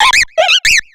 Cri de Capidextre dans Pokémon X et Y.